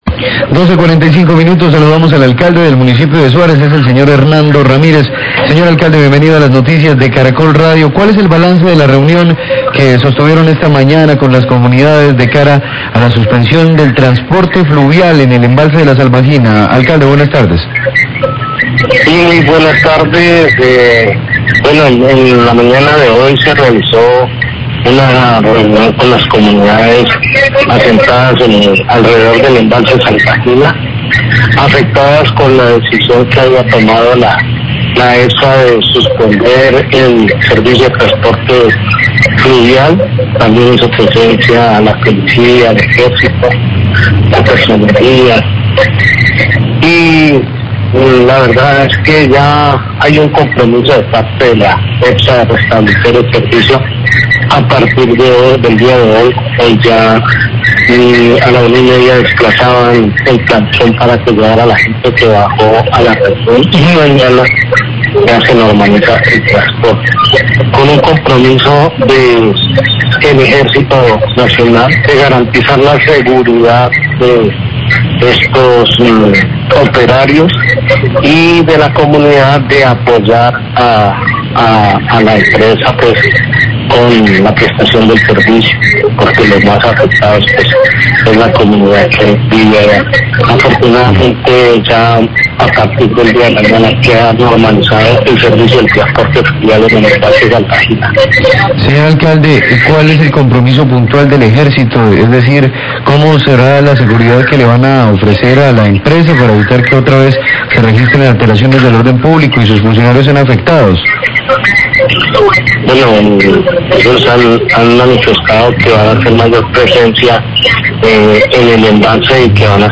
Radio
El alcalde de Suárez, Hernando Ramírez, hace un balance de la reunión realizada en Morales con la comunidad, Policía, Ejército, Personería. Epsa se comprometió a restablecer el servicio de transporte fluvial sobre La Salvajina, a partir de hoy. El Ejército se comprometió a garantizar la seguridad de los operarios y de la comunidad.